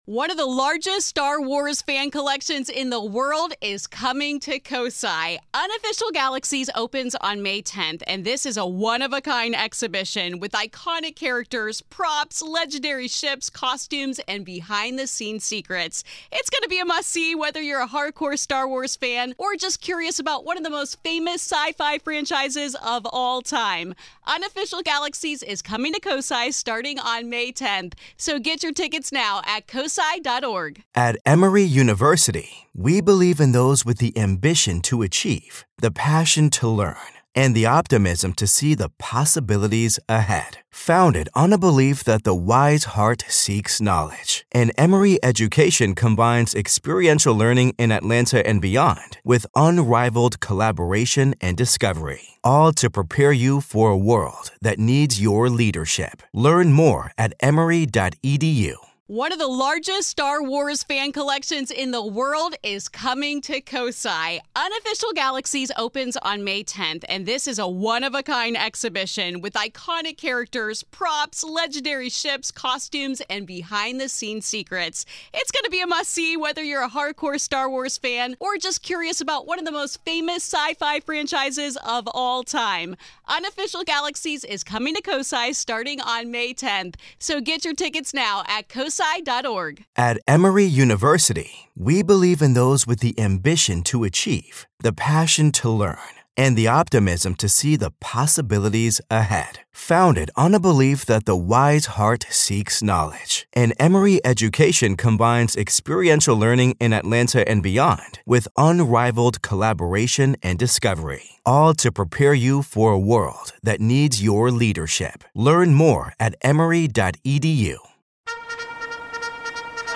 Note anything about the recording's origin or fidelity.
He also gets nostalgic about sibling pranks, summers on the Kanawha River, and getting to see local high school sports legends Jason Williams and Randy Moss in person. And for the first time in The Wayback, we get a special musical performance